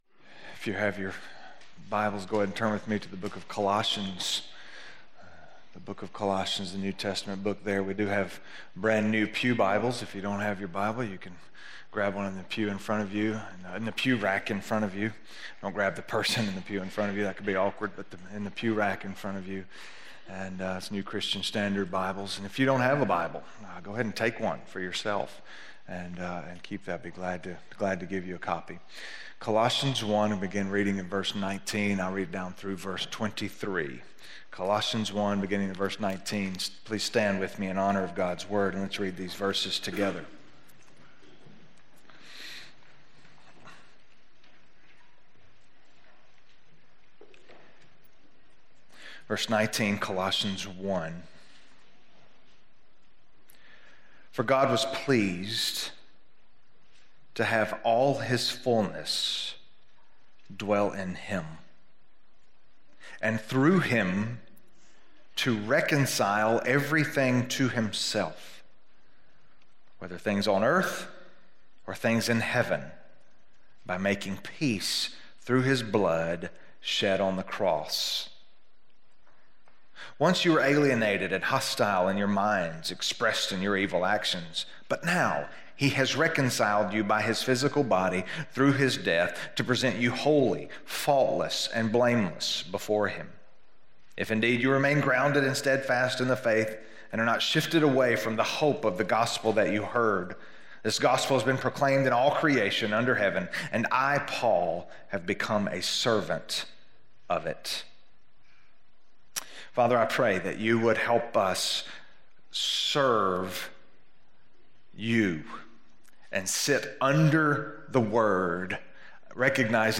We Can Know...Contentment - Sermon - West Franklin